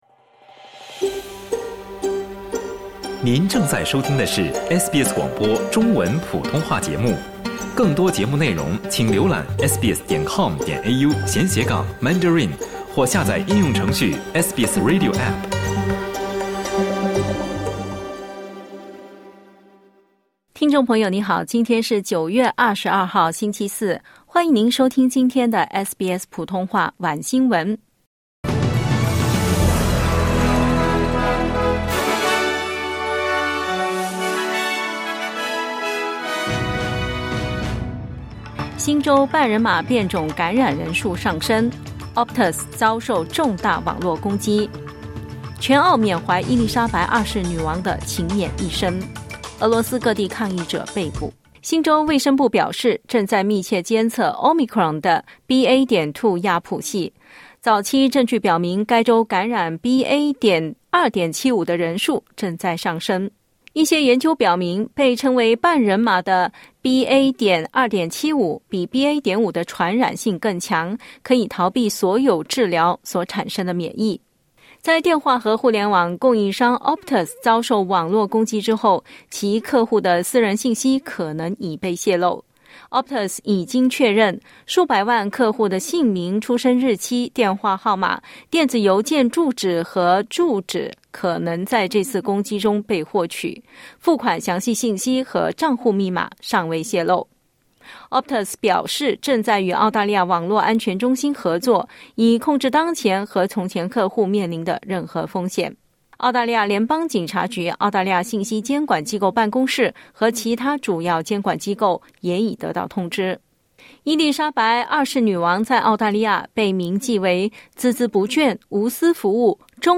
SBS 晚新闻 （22/09/2022）